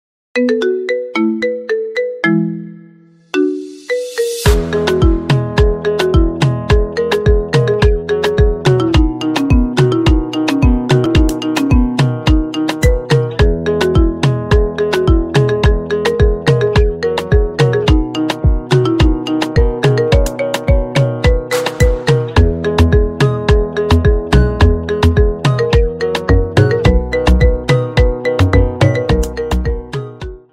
Pop & Rock
Marimba Remix